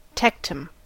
Ääntäminen
Ääntäminen US Tuntematon aksentti: IPA : /ˈtɛk.təm/ Haettu sana löytyi näillä lähdekielillä: englanti Käännöksiä ei löytynyt valitulle kohdekielelle.